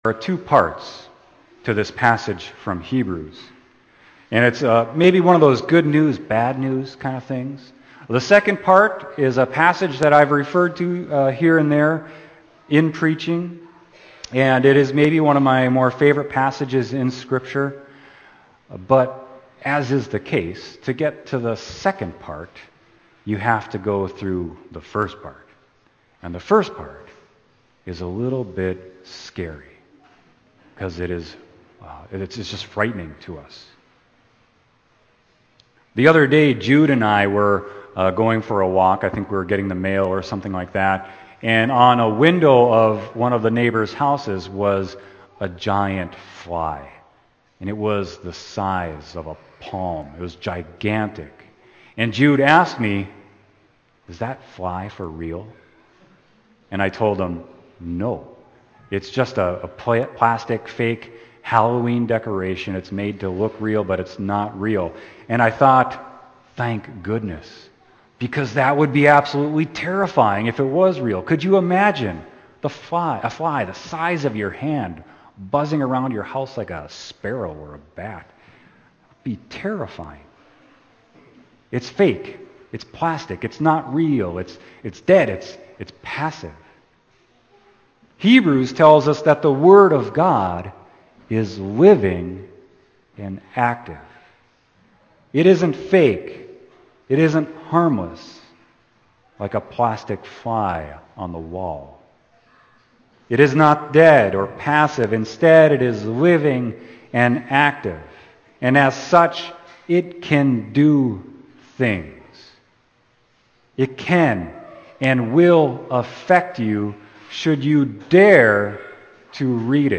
Sermon: Hebrews 4.12-16